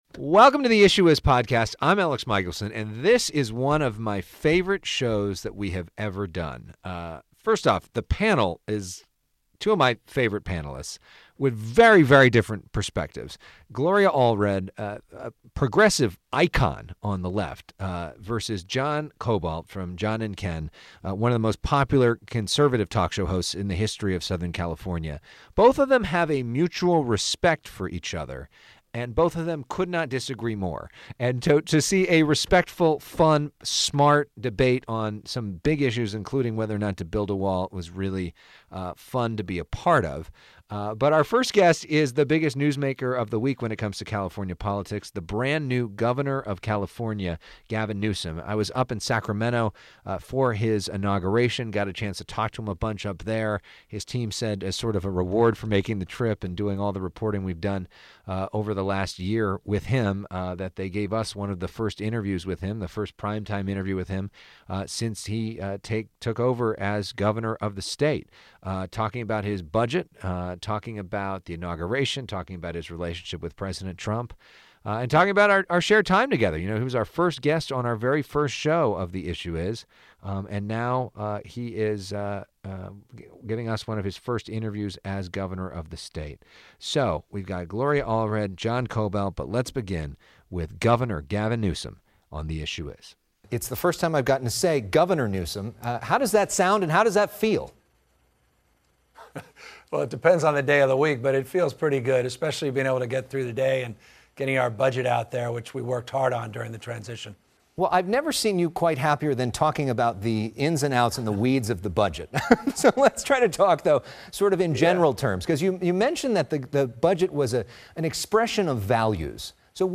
California Governor Gavin Newsom joins us for his first prime-time interview since taking office.